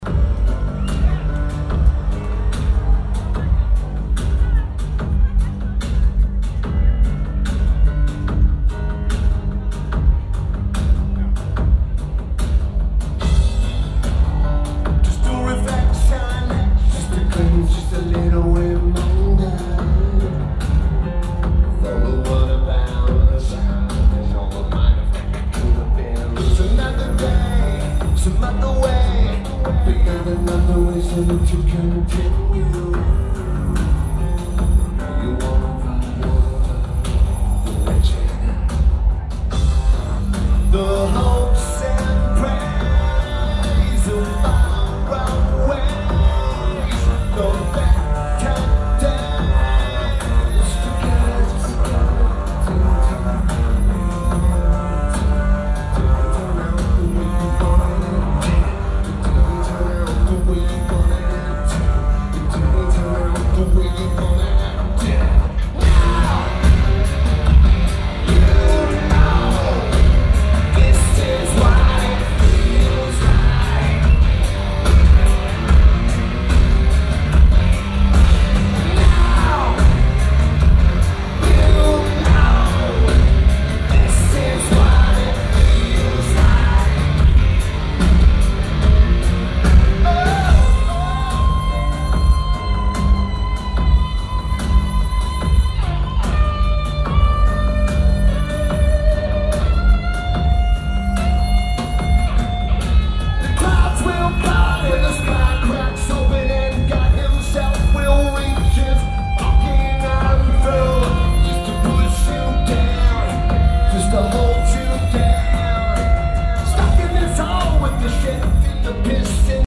The Palladium